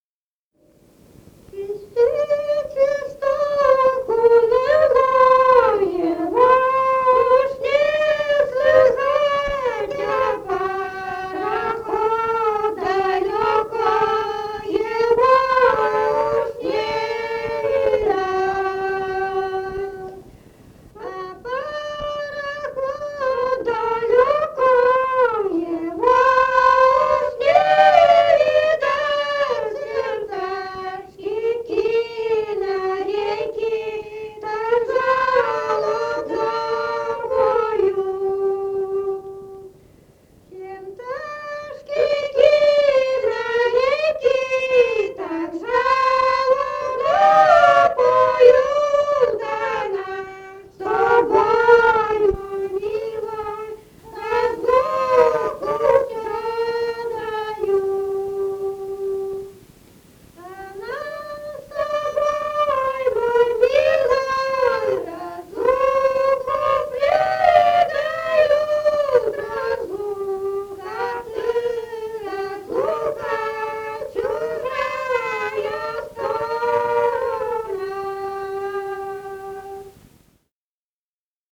«Свистит свисток уныло» (лирическая).